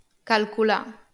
or. IPA[kəɫkuˈɫa]
n-occ. IPA[kaɫkuˈɫa]
val. IPA[kaɫkuˈɫaɾ]